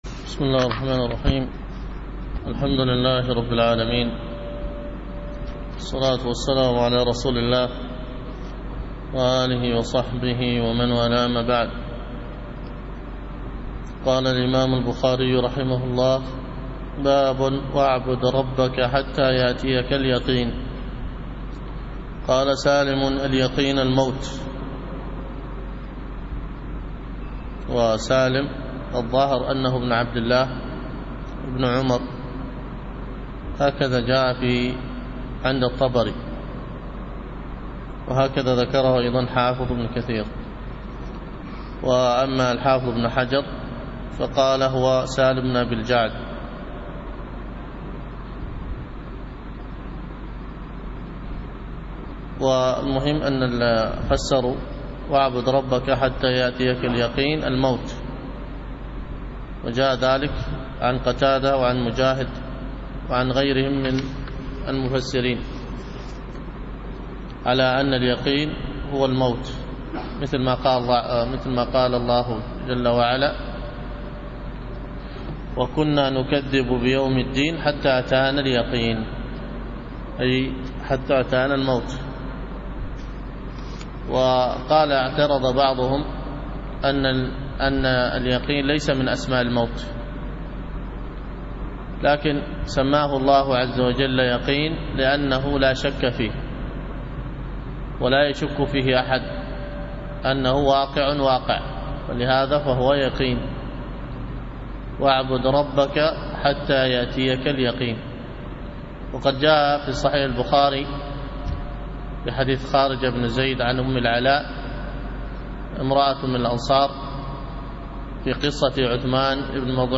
الدروس الفقه وأصوله